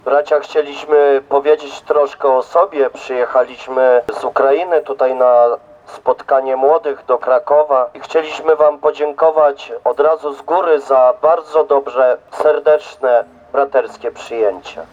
A podziękowania popłynęły również w formie muzycznej.
pielgrzymi podziękowania pod pomnikiem.mp3